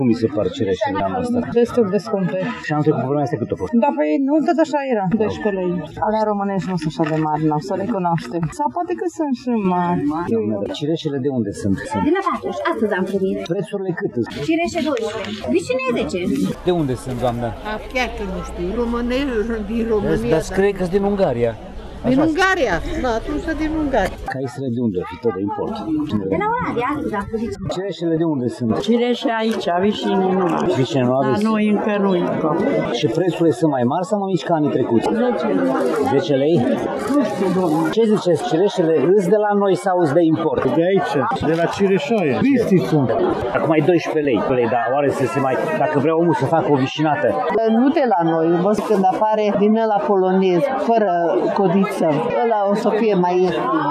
Prețurile pe kilogram este aproximativ la fel anul trecut, între 10 și 12 lei, iar vânzătorii spun că nu au șanse să ieftinească fructele, pentru că sezonul lor e scurt, de maximum trei săptămâni: